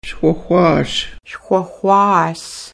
Listen to the elder